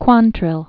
(kwŏntrĭl), William Clarke 1837-1865.